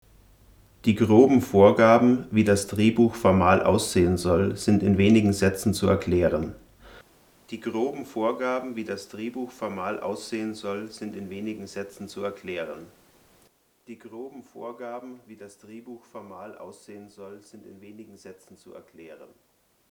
Ich habe drei Richtmikrofone unter "Angel-Bedingungen" getestet, d. h. in einem akustisch nicht besonders ausgestatteten Wohnzimmer aus ziemlich genau 1 Meter Distanz schräg von oben:
Hier ein Direktvergleich der Ausgangspegel (nacheinander Rode, t.Bone und Hama):
Als Vorverstärker kam für meinen Test ein Mischpult mit rauscharmem Mic-Preamp zum Einsatz; unter realen Bedingungen (z. B. ein nicht so rauscharmer Camcorder-Mikrofoneingang) wäre das Rauschen daher noch höher - insbesondere bei den beiden billigeren Mikrofonen mit ihrem niedrigen Ausgangspegel.
pegelvergleich.mp3